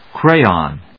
/kréɪɑn(米国英語), kréɪɔn(英国英語)/